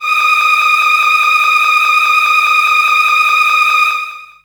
55be-syn20-d#5.aif